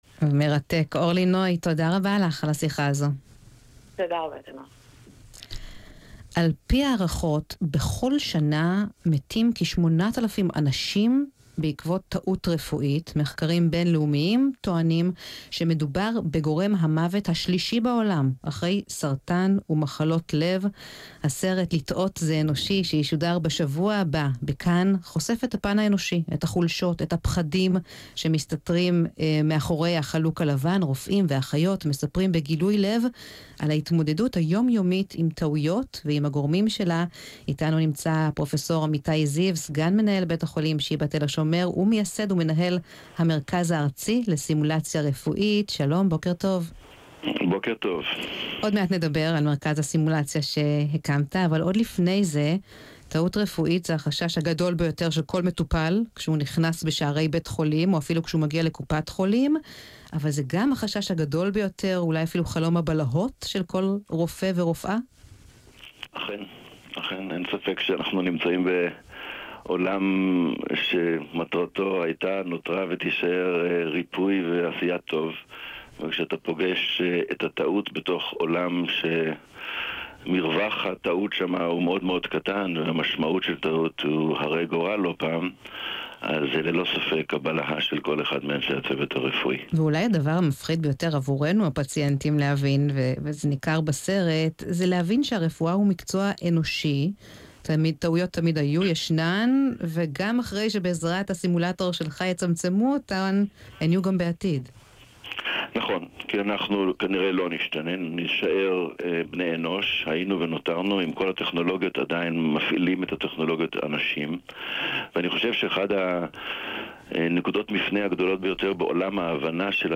ינואר 2018 - ראיון בגלי צה
ראיון בגלי צה"ל